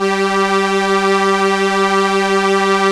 Index of /90_sSampleCDs/Roland LCDP09 Keys of the 60s and 70s 1/STR_ARP Strings/STR_ARP Solina